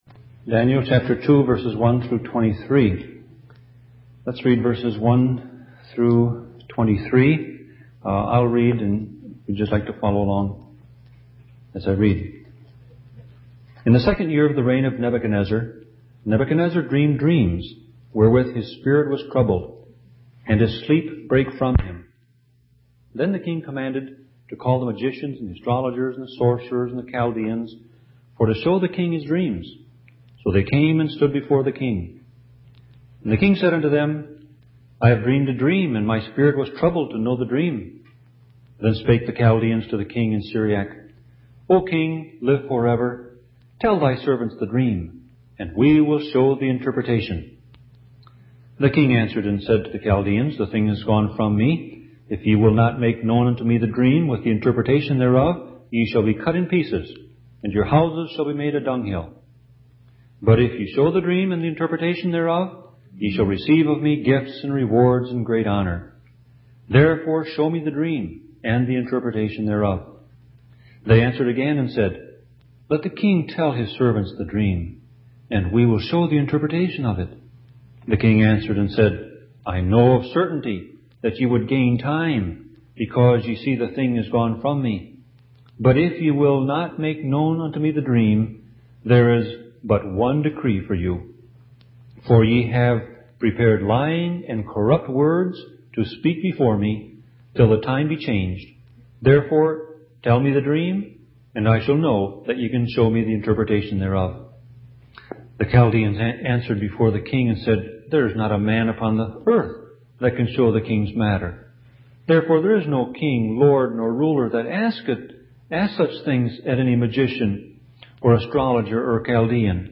Sermon Audio Passage: Daniel 2:1-23 Service Type